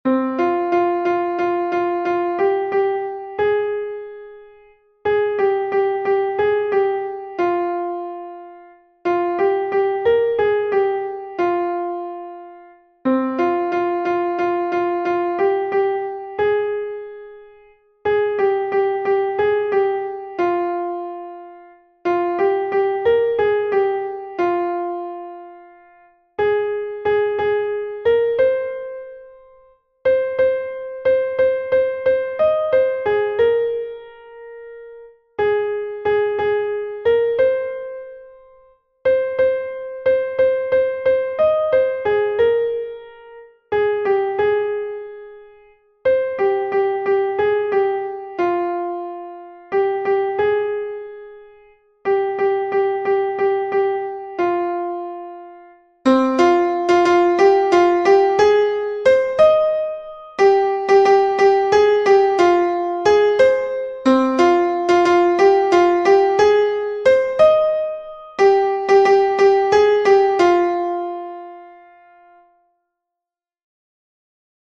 Fichier son soprano 1
Nerea-izango-zen-Laboa-soprano-1-V3-1.mp3